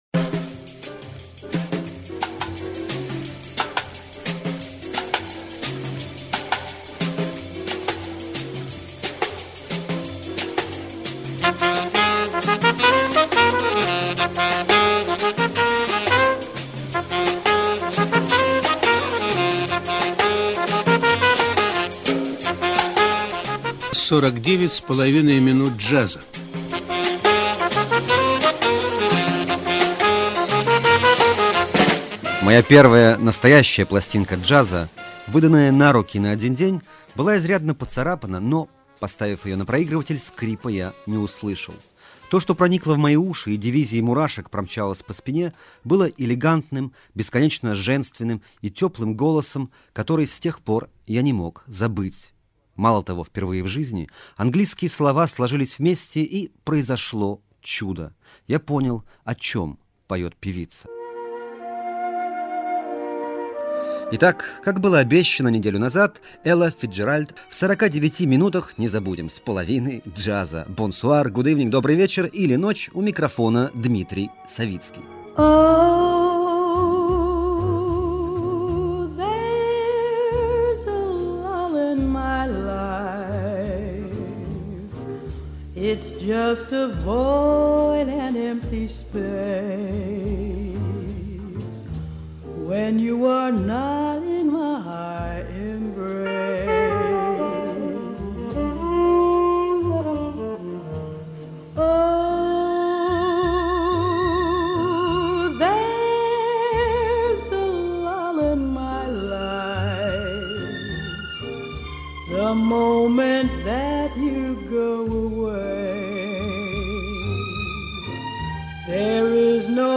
Легендарная джазовая певица и знаменитый композитор